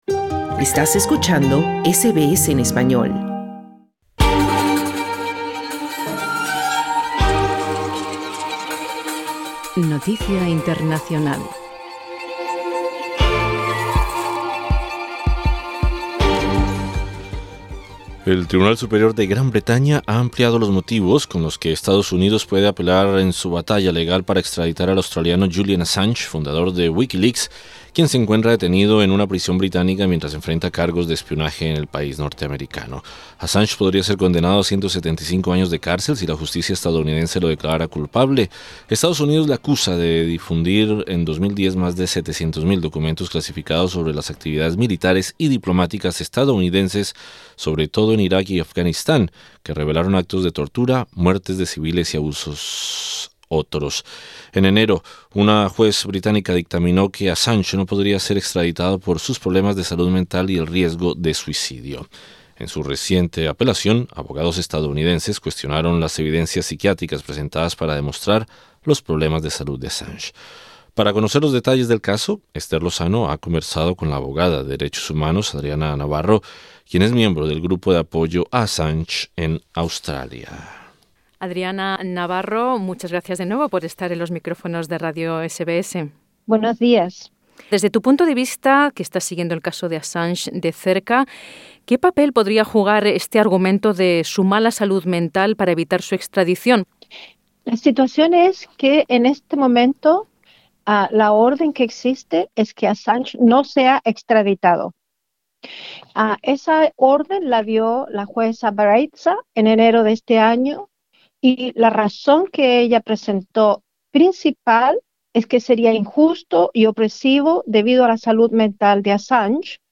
Abogada de derechos humanos clama al gobierno de Morrison